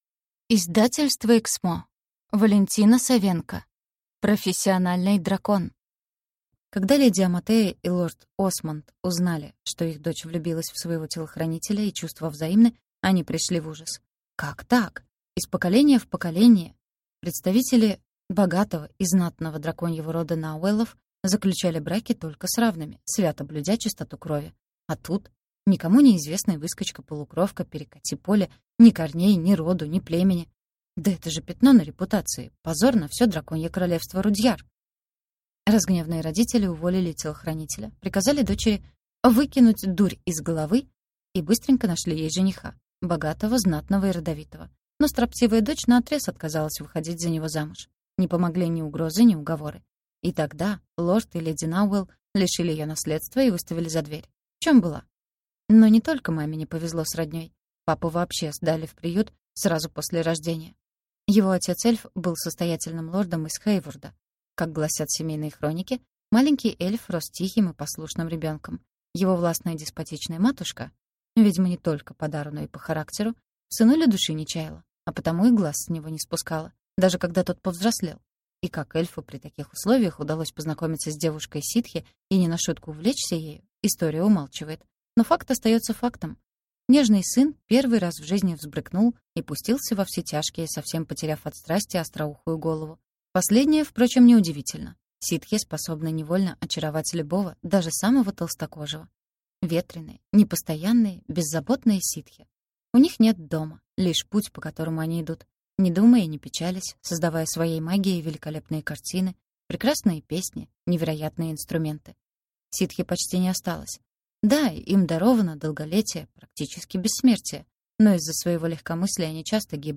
Аудиокнига Профессиональный дракон | Библиотека аудиокниг